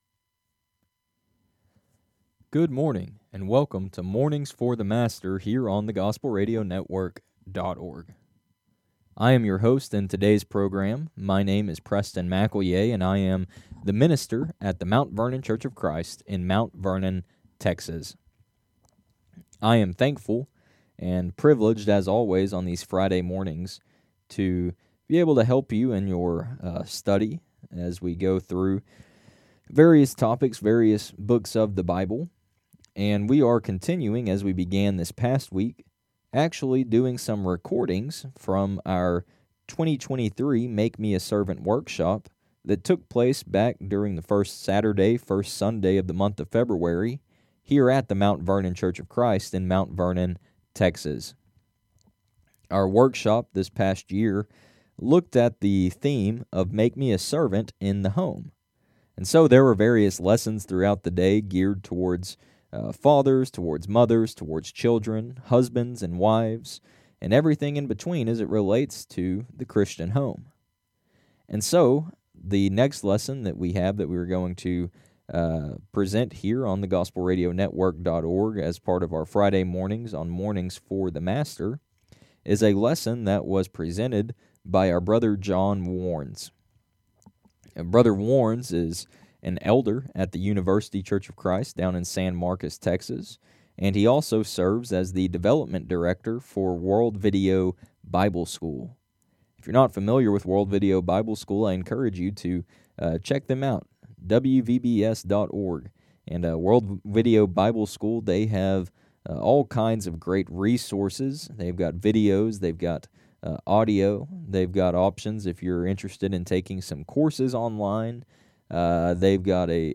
The Loving Husband (from 2023 Make Me A Servant Workshop)